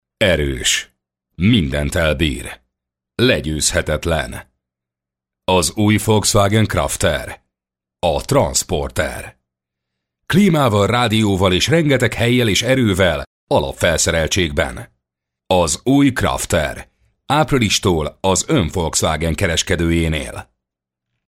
Hungarian, ungarische voice talent, sprecher, deep, east-european, warm
Sprechprobe: Sonstiges (Muttersprache):
Hungarian voice actor, voice talent, deep, warm